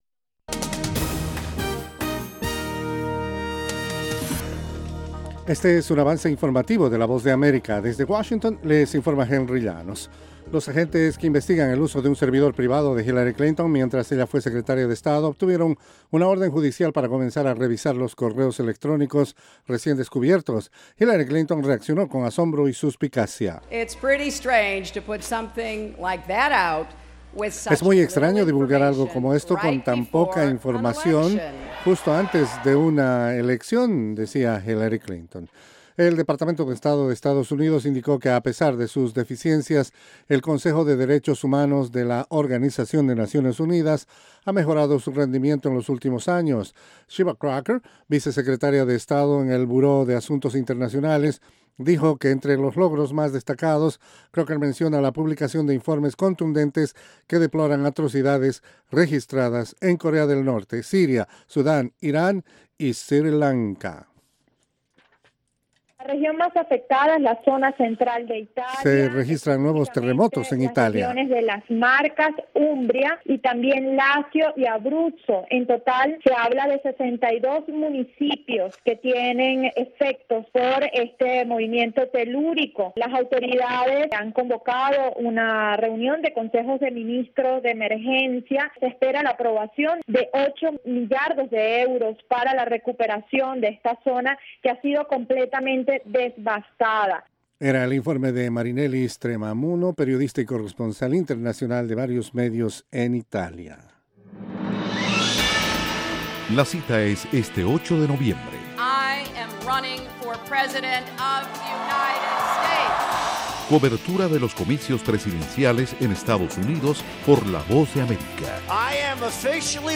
Capsula informativa de 5 minutos con el acontecer noticioso de Estados Unidos y el mundo.